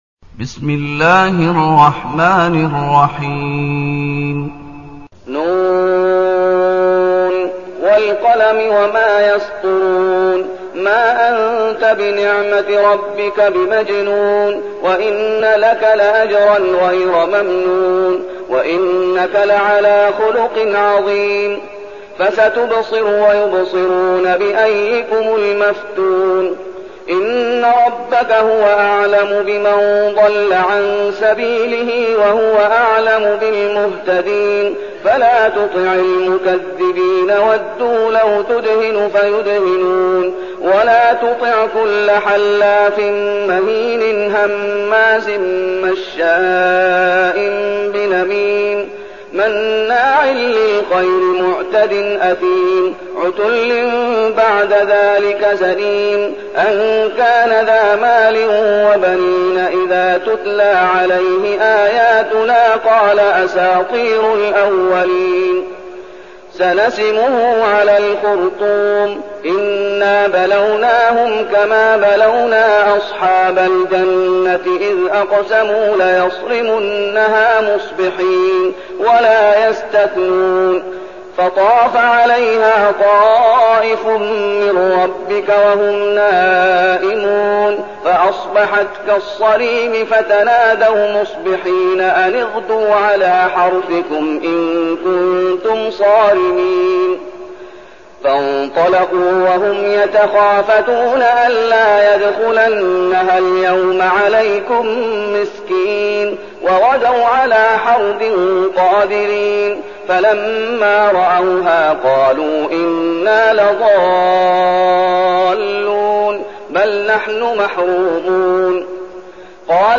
المكان: المسجد النبوي الشيخ: فضيلة الشيخ محمد أيوب فضيلة الشيخ محمد أيوب القلم